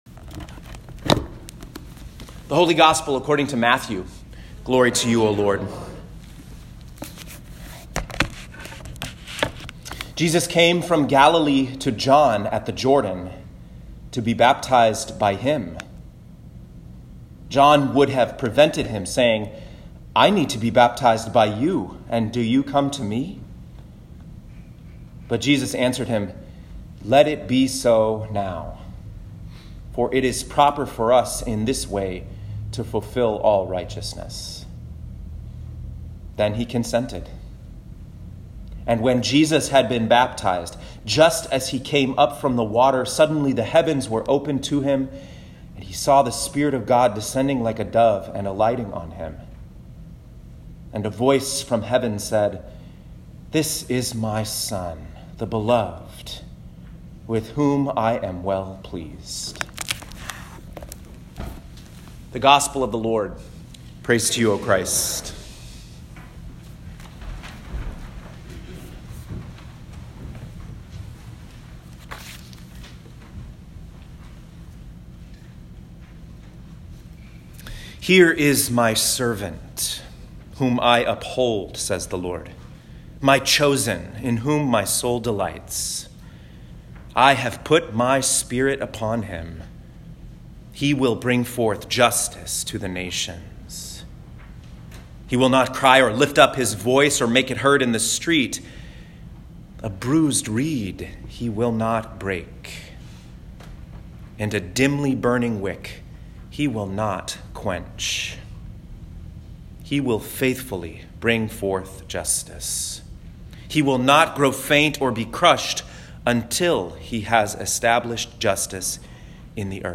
Baptism of Our Lord, Year A (1/12/2020) Isaiah 42:1-9 Psalm 29 Acts 10:34-43 Matthew 3:13-17 Click the play button to listen to this week’s sermon.